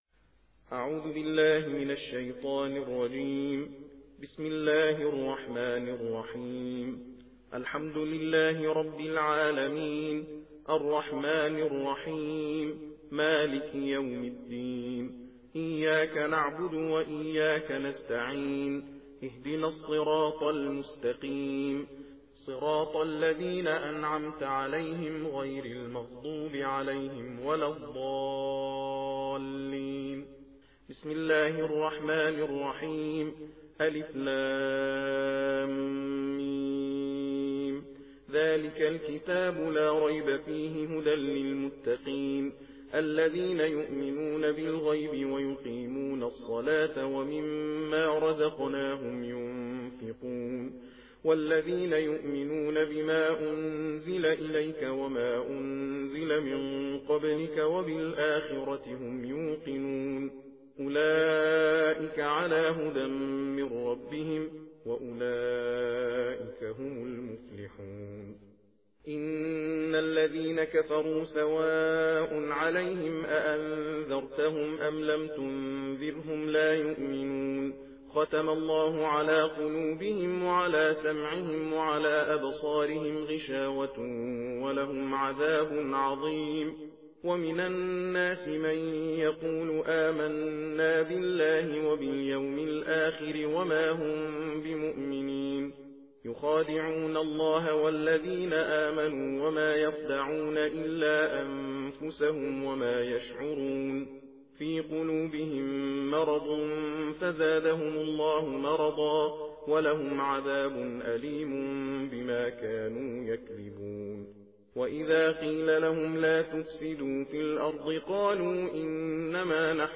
صوت/ تندخوانی جزء اول قرآن کریم